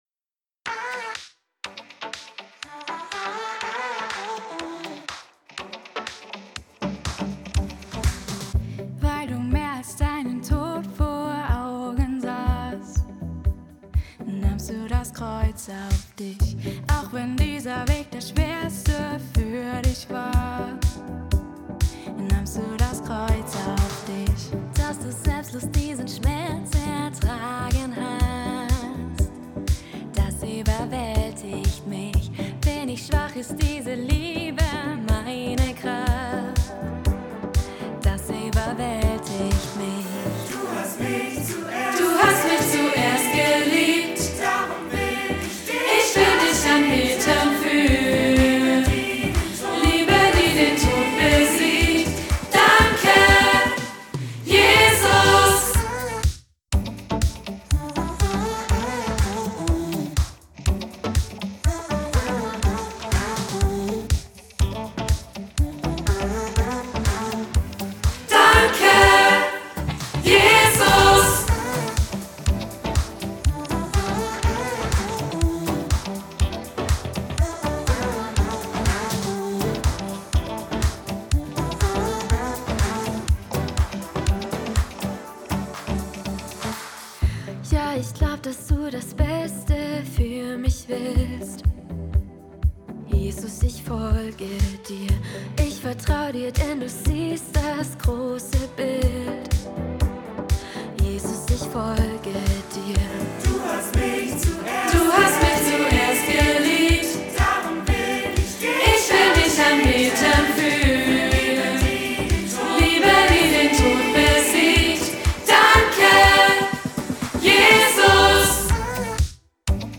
Audiospur Alt